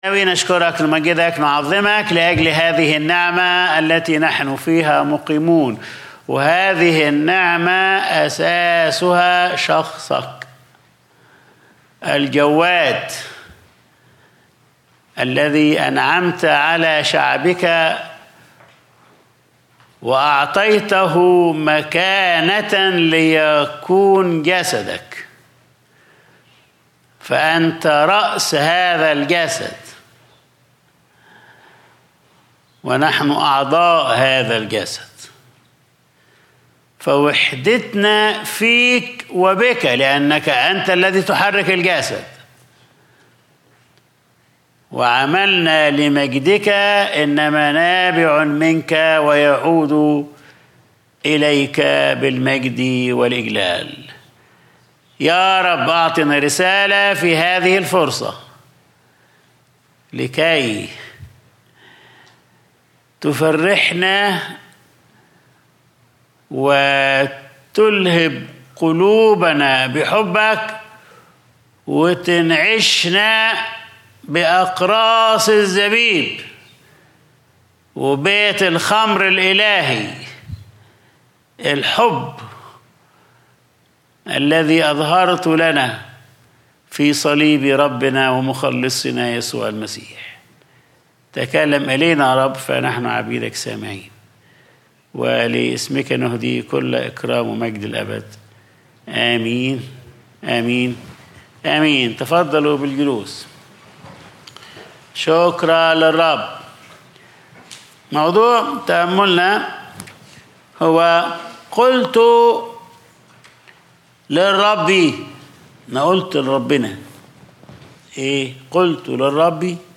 Sunday Service | قُلت للرب أنت سيدي